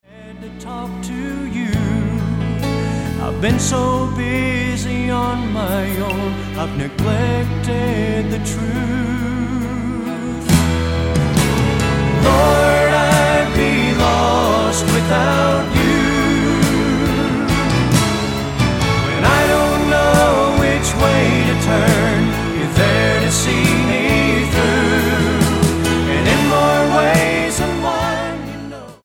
STYLE: Southern Gospel
tender harmonies